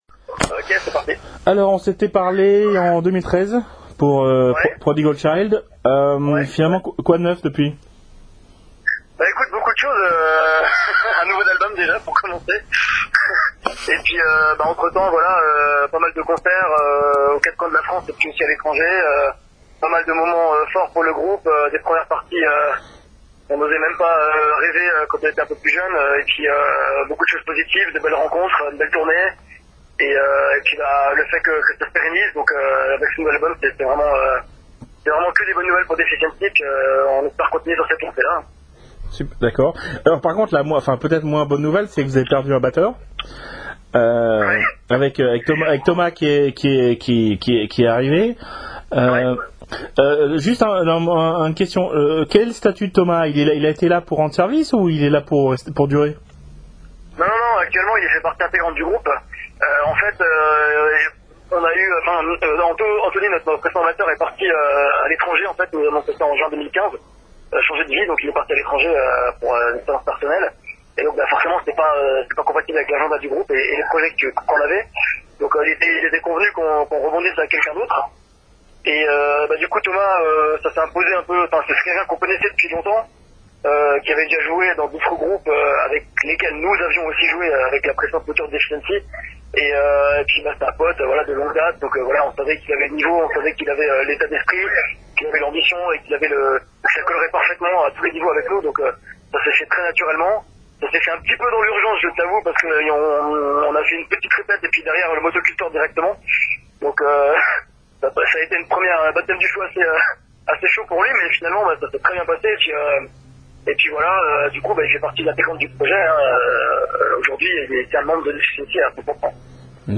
DEFICIENCY (interview